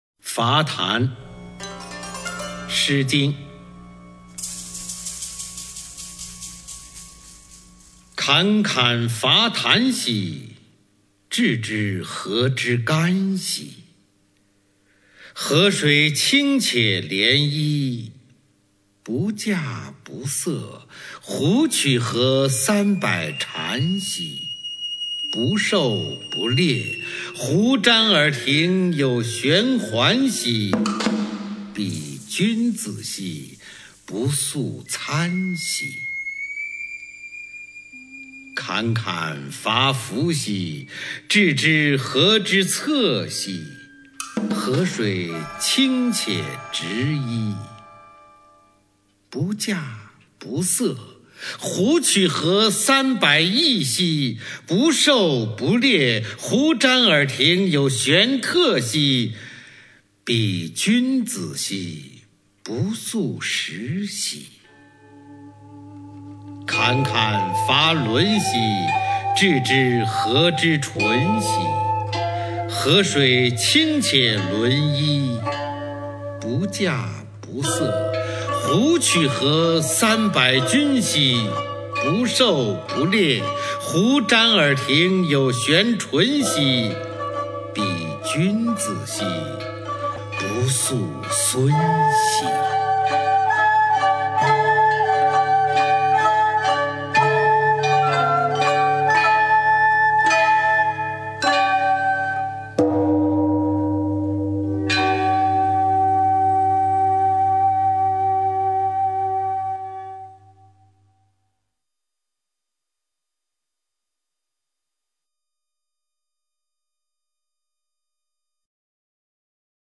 [先秦诗词诵读]诗经-伐檀 朗诵